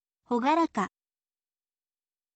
hogaraka